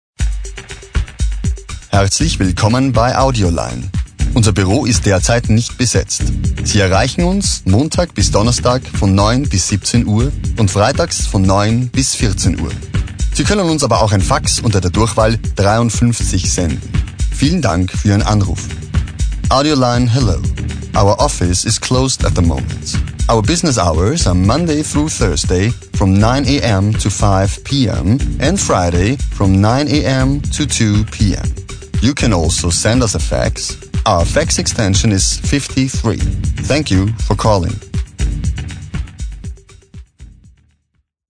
SPRECHER DEMO Hochdeutsch - ANRUFBEANTWORTER GOSH AUDIO.mp3